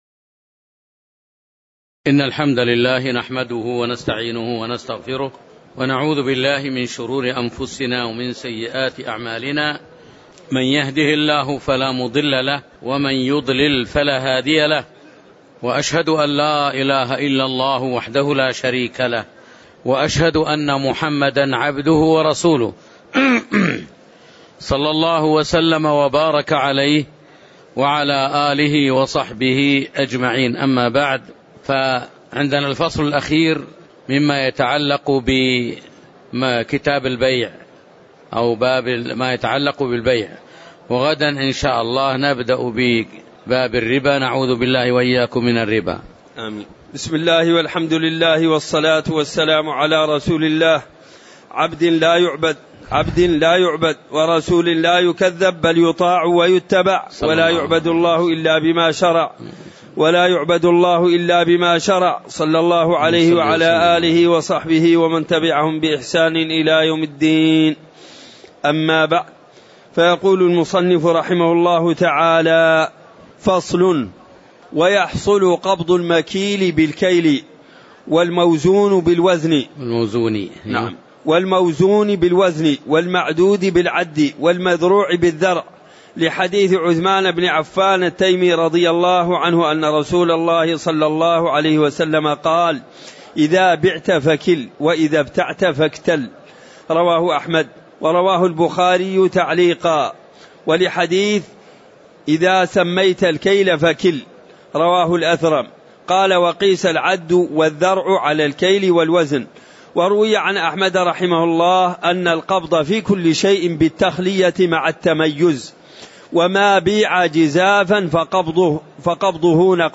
تاريخ النشر ٢ صفر ١٤٤٠ هـ المكان: المسجد النبوي الشيخ